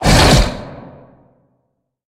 Sfx_creature_bruteshark_chase_os_05.ogg